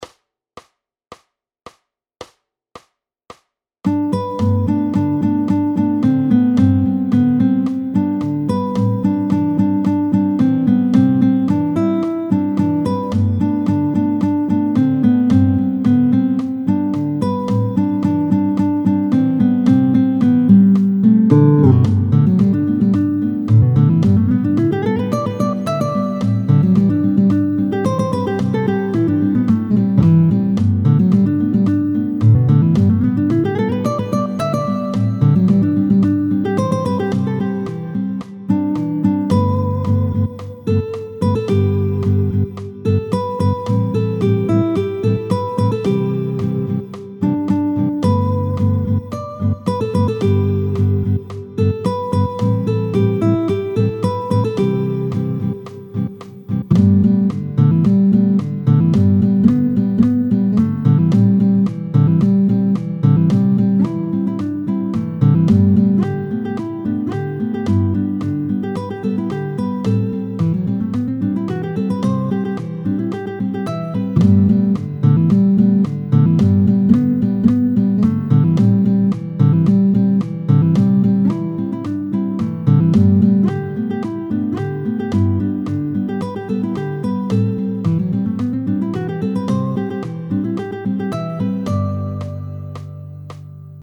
Nous sommes donc en Sol.
tempo 110